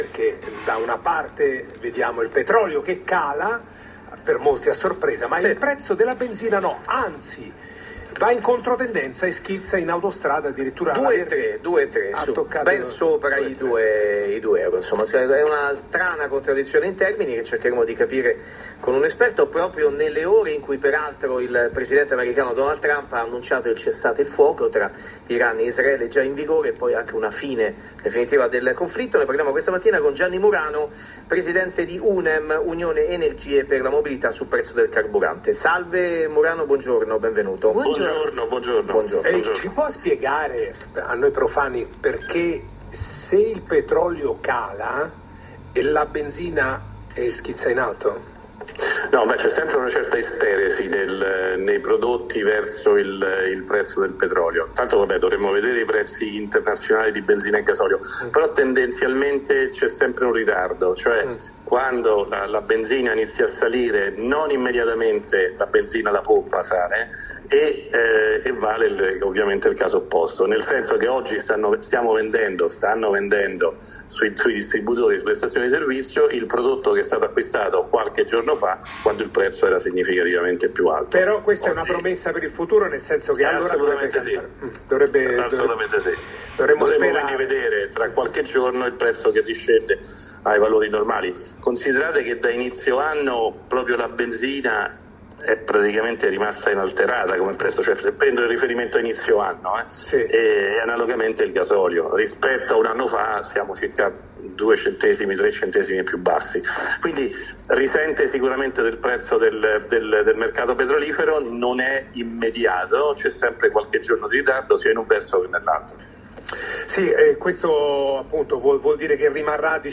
Radio Capital